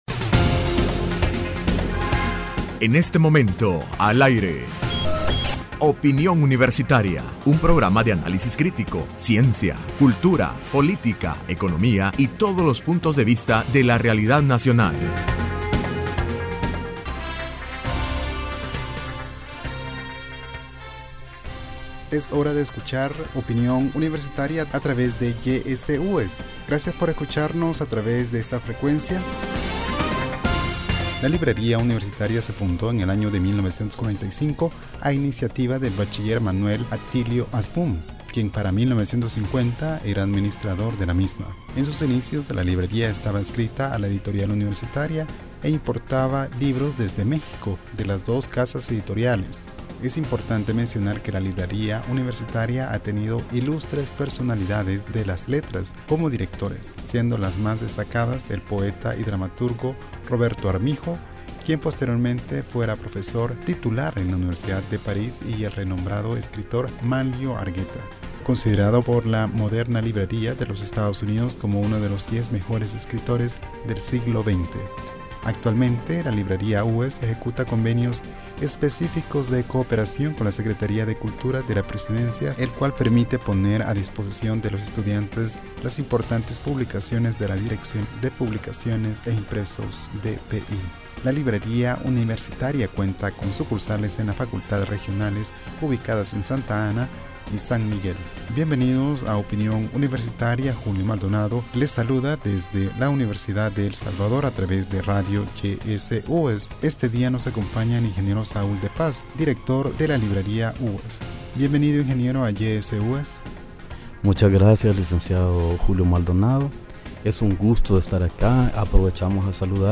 Entrevista Opinión Universitaria(17 Mayo 2016) : Función realizada por la librería de la UES.